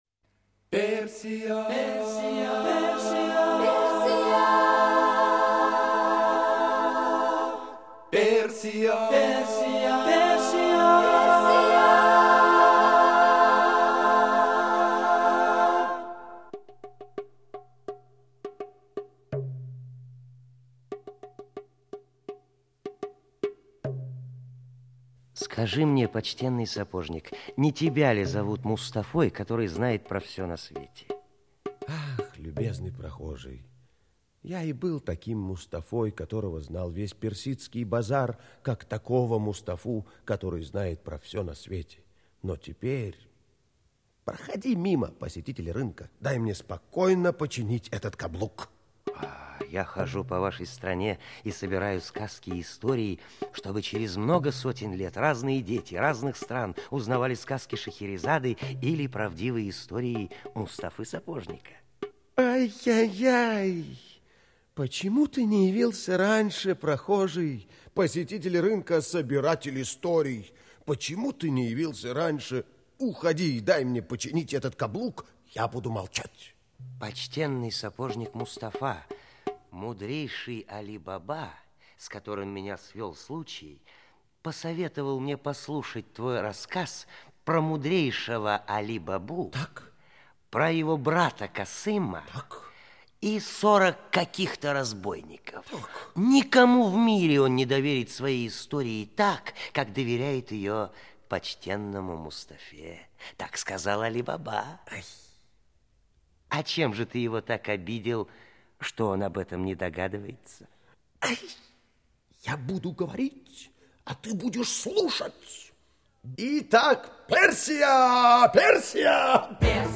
Музыкальная сказка
В массовых сценах — артисты московских театров